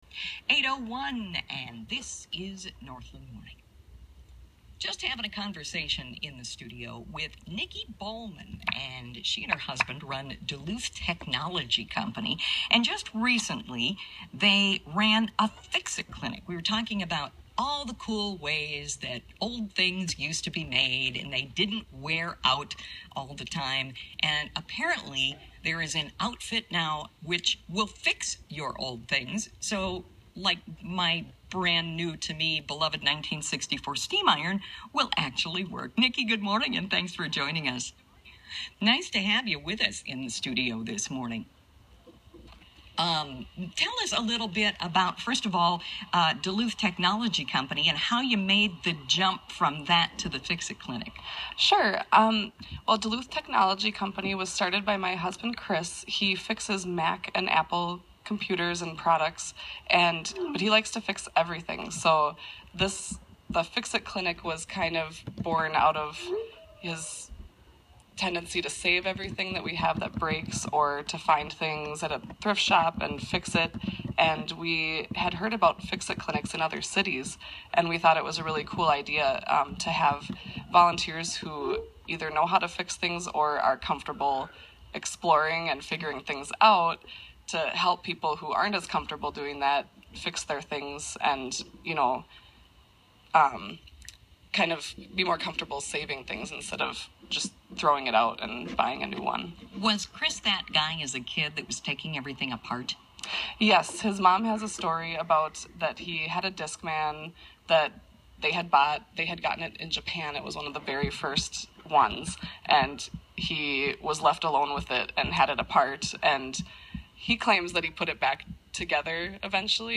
I was working onsite, out of town, and pinched for time, so the best I could do was record it on my phone which was sitting next to my computer. Not normally my style but the iPhone 6’s speakerphone mic did exceptionally well.
KUMD-Morning-Fix-it-Clinic.wav